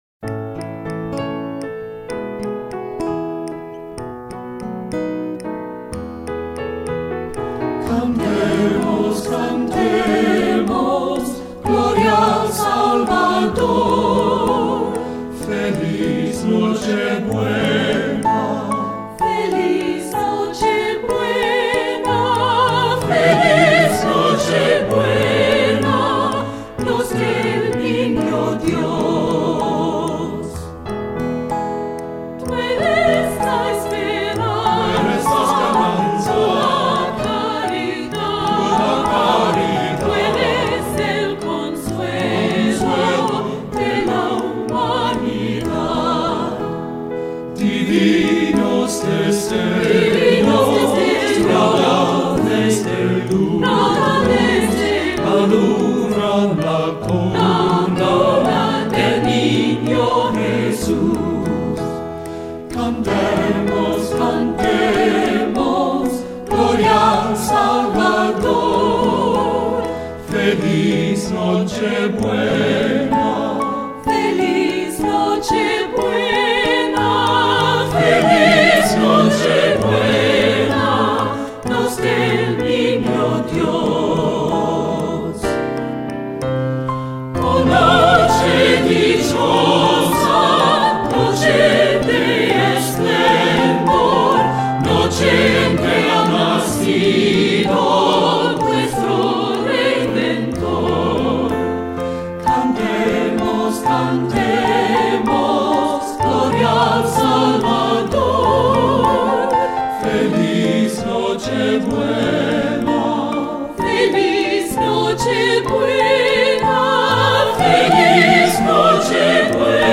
Composer: Venezuelan Folk Carol
Voicing: 2-Part